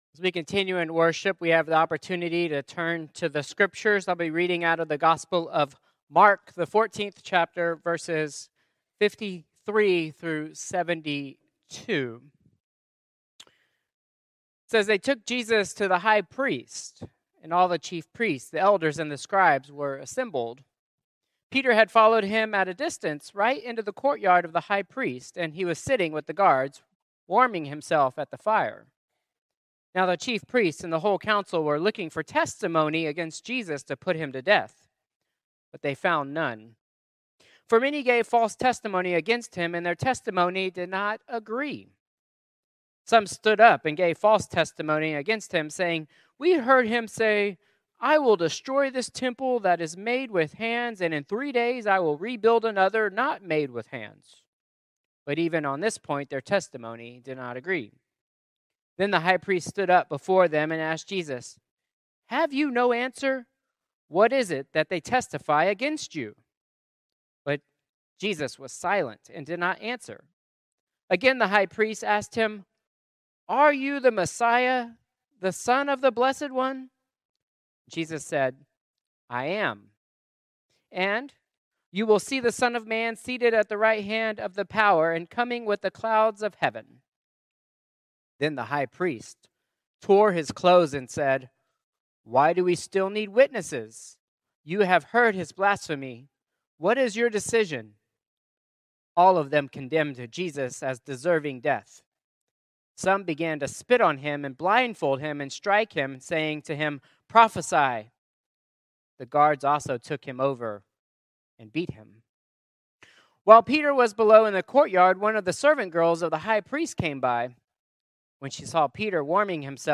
Traditional Worship 3-1-2026